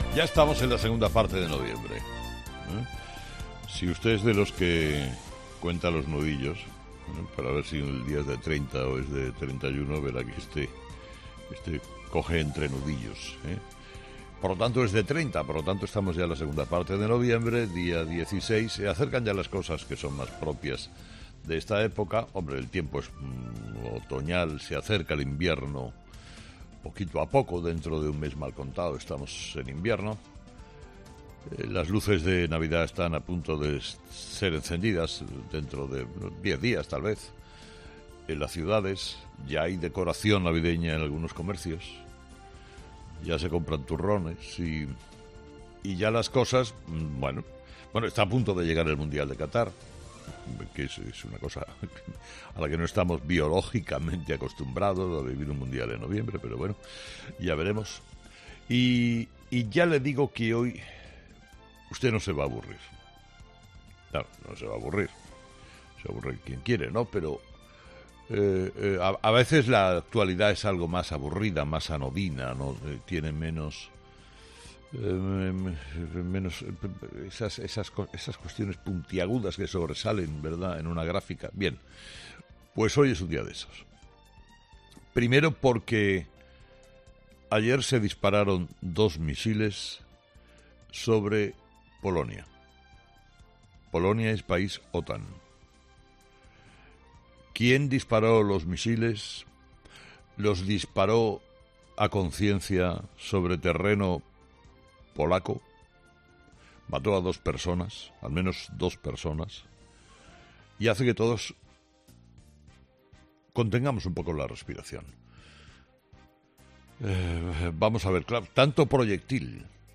Carlos Herrera repasa los principales titulares que marcarán la actualidad de este miércoles 16 de noviembre en nuestro país